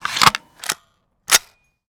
minigun_reload_01.wav